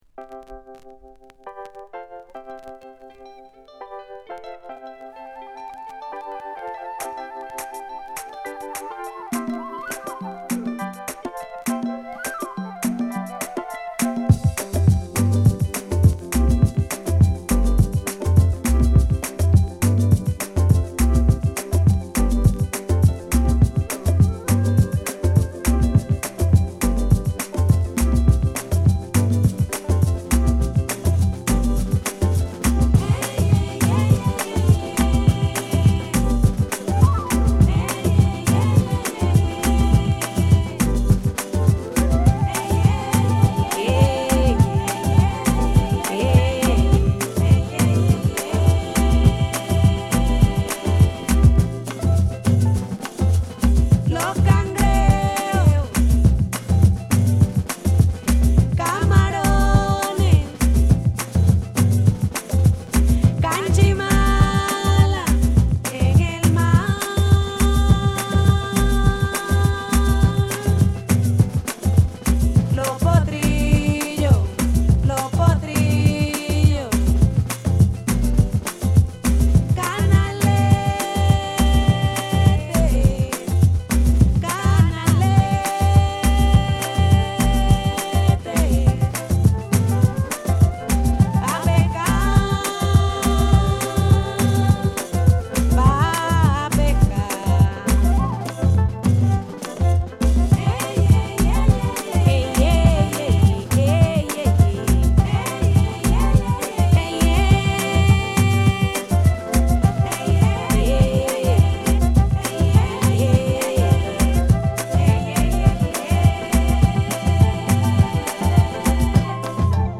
Cumbia , Latin , Mellow Groove